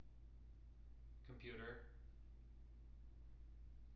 wake-word
tng-computer-342.wav